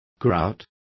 Complete with pronunciation of the translation of grouts.